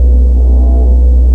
ElectricBox.wav